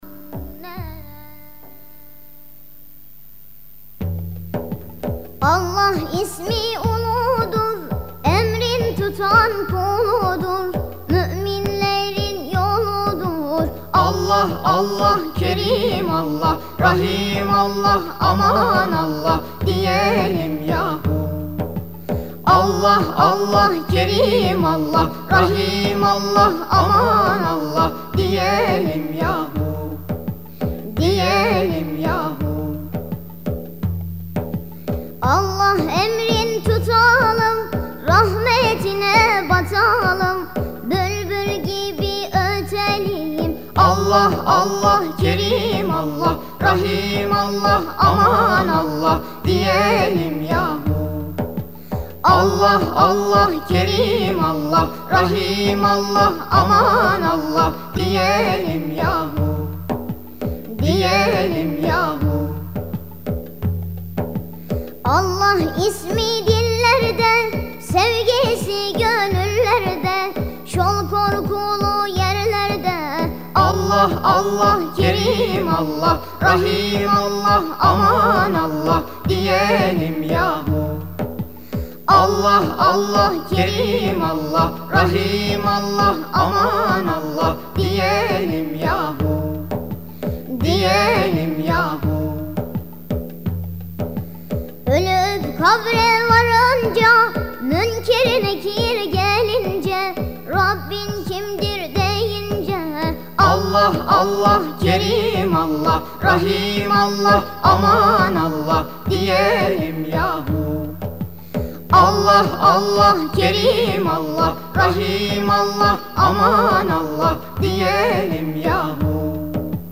๑ஜ๑ஜ๑ مجموعه ای از تواشیح زیبا ๑ஜ๑ஜ تواشیح زیبای الهی یا متعالی